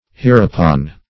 Meaning of hereupon. hereupon synonyms, pronunciation, spelling and more from Free Dictionary.